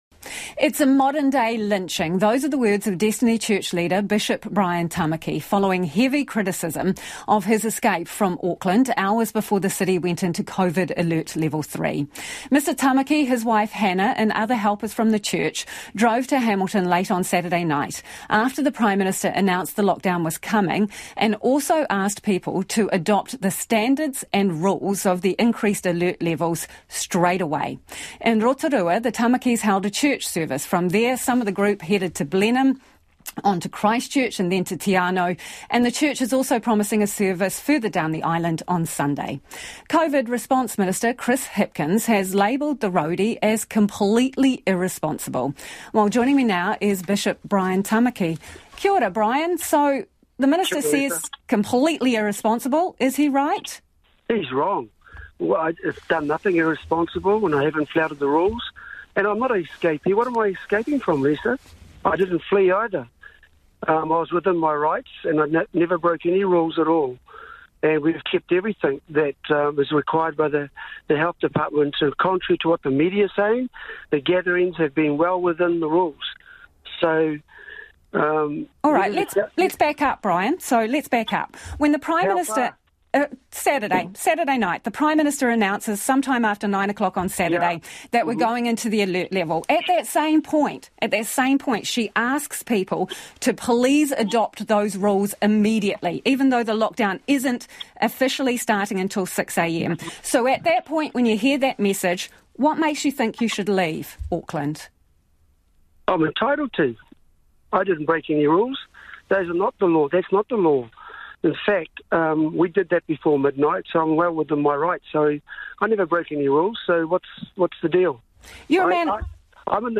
A friend alerted me to a Radio NZ interview with the progressive media’s favourite object of hatred, Pastor Brian Tamaki. The “interviewer” was hard leftist Lisa Owen, and she harried and harassed Tamaki through the whole interview in a totally unprofessional and personally prejudicial manner.
lisa-owen-brian-tamaki-reduced.mp3